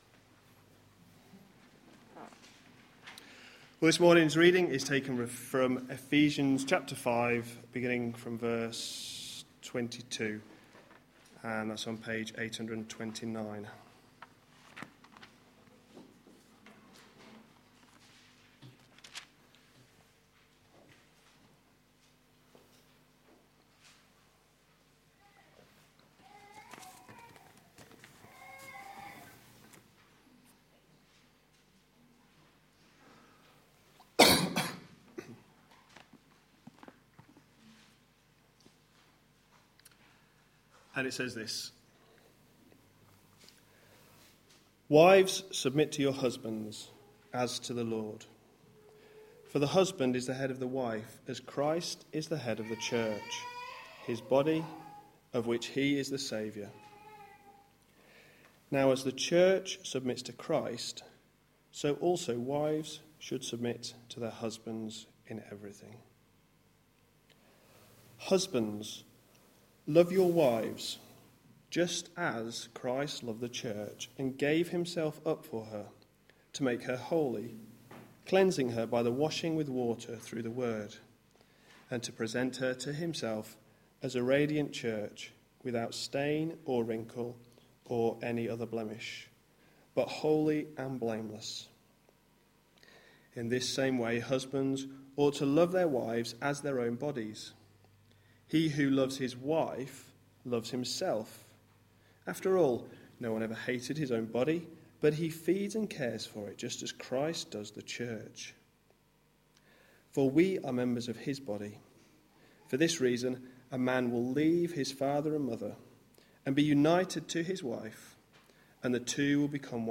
A sermon preached on 7th September, 2014, as part of our How do Christians think about... ? series.